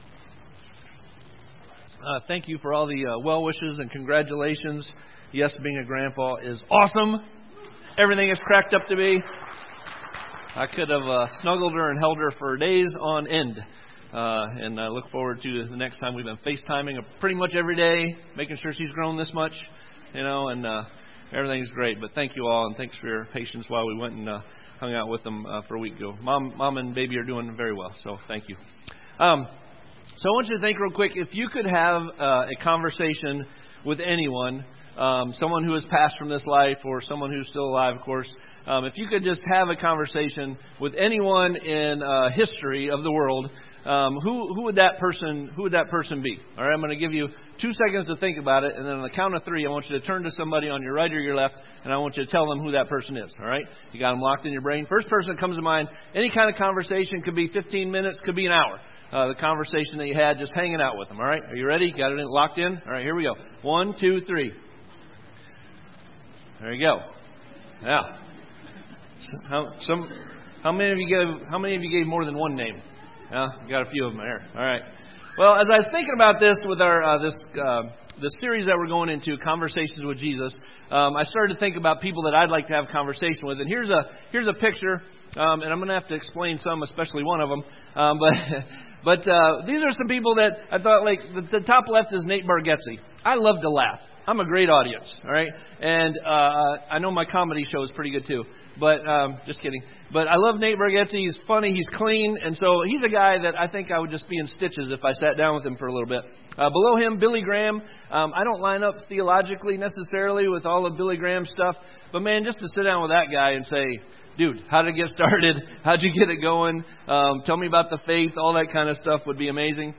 Sermon Series: Conversations with Jesus